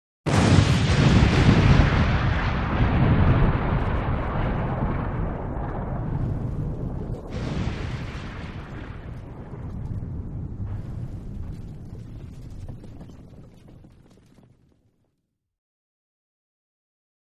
Building Demolition 2; Demolition; Enormous Echoing Explosion Followed By Falling Debris, Medium To Close Perspective.